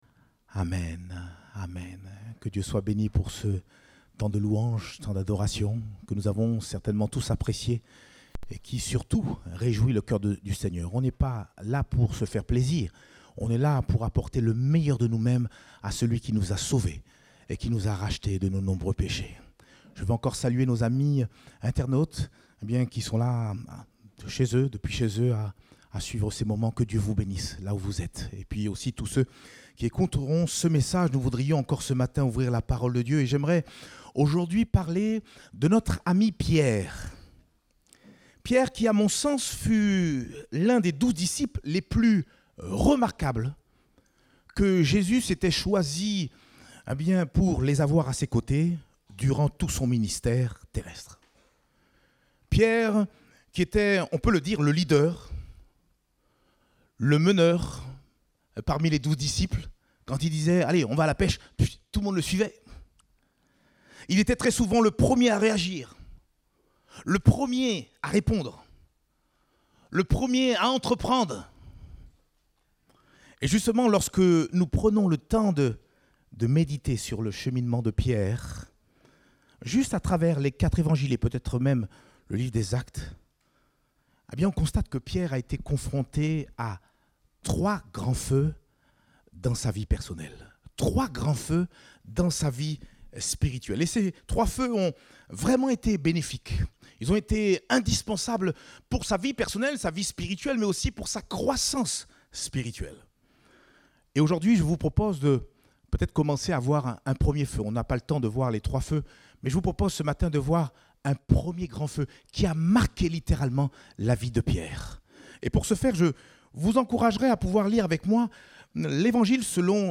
Date : 24 octobre 2021 (Culte Dominical)